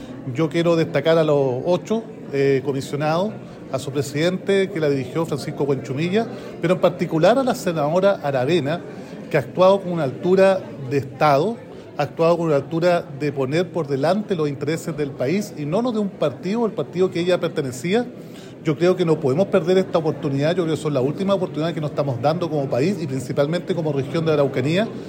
Por su parte, el alcalde de Temuco, Roberto Neira, junto con agradecer el trabajo de los 8 comisionados, encabezados por el senador, Francisco Huenchumilla, relevó la actitud de la senadora Carmen Gloria Aravena quien renunció a su colectividad Republicanos, para impedir recibir presiones en su voto